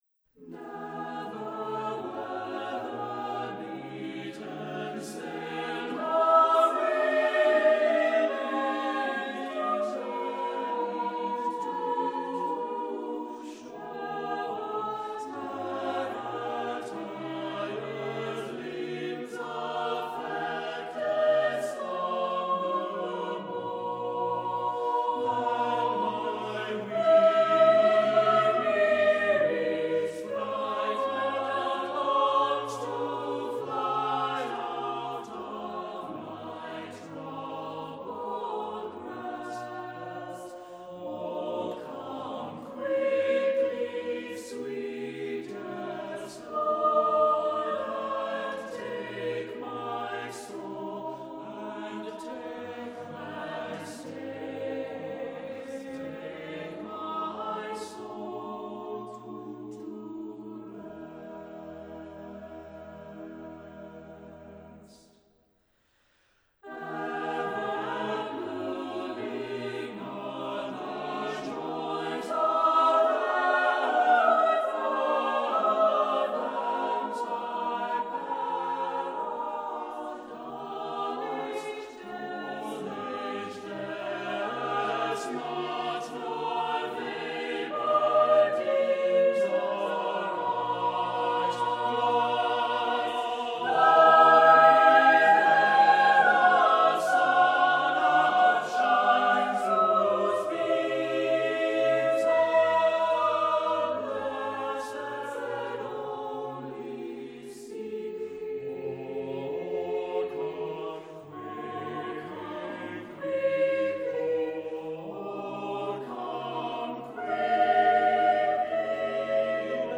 Accompaniment:      Reduction
Music Category:      Christian